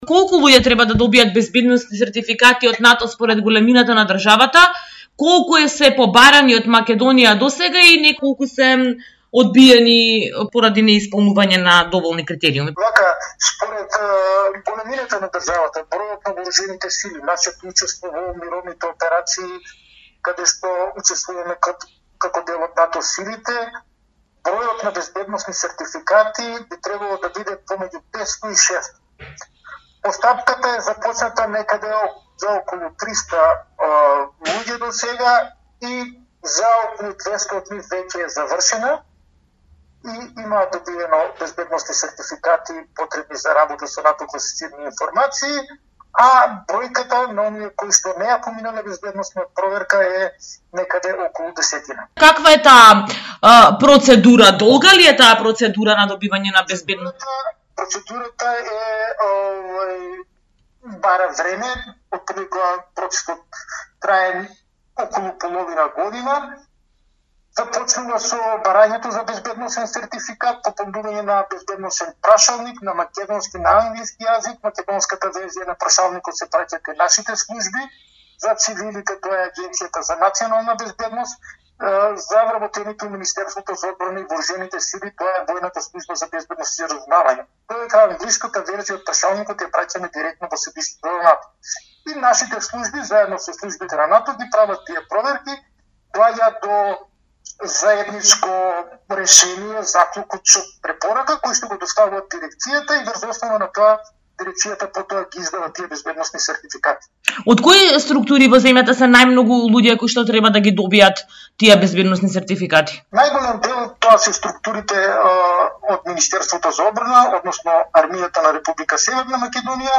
Радио интервју со Стојан Славески: